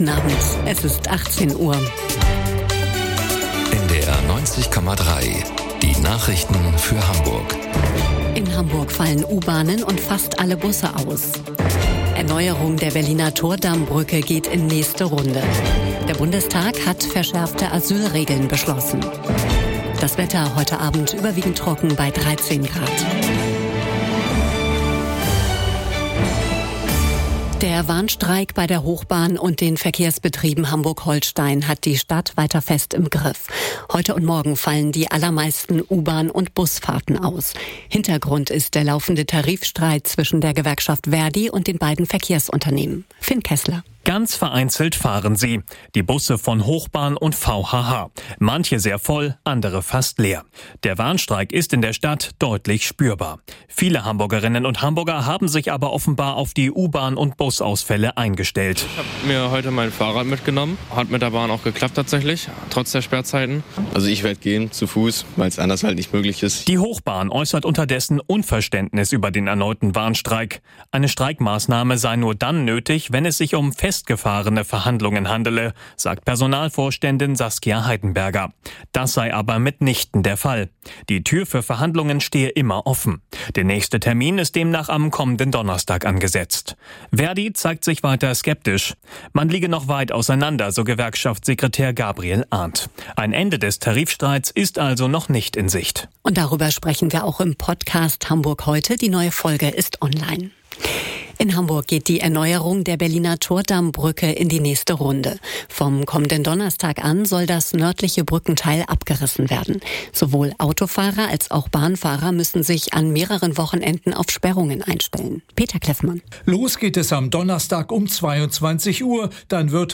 Aktuelle Ereignisse, umfassende Informationen: Im Nachrichten-Podcast von NDR 90,3 hören Sie das Neueste aus Hamburg und der Welt.